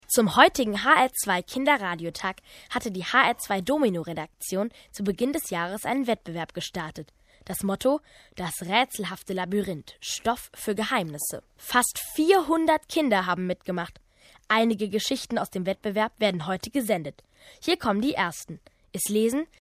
junge deutsche Sprecherin für Rundfunksendungen und Hörspiele.
Junge Stimme
Sprechprobe: eLearning (Muttersprache):
young female german voice over artist